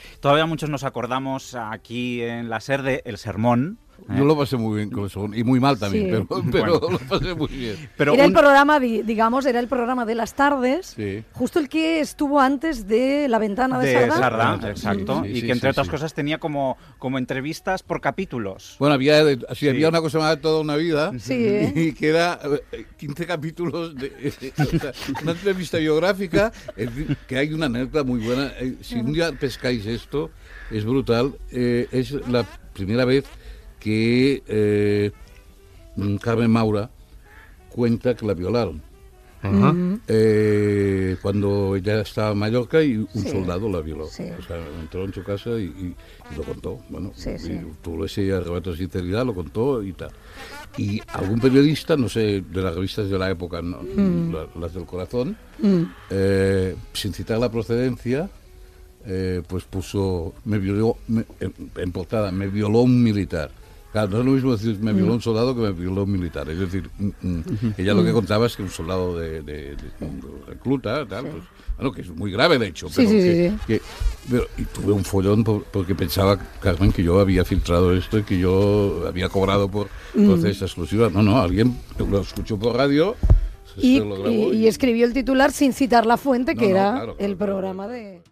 Entrevista al presentador Àngel Casas, que recorda el programa "El sermón" de la Cadena SER i una entrevista que va fer a l'actriu Carmen Maura
Info-entreteniment